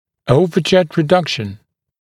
[‘əuvəʤet rɪ’dʌkʃn][‘оувэджэт ри’дакшн]уменьшение горизонтального резцового перекрытия, уменьшение сагиттальной щели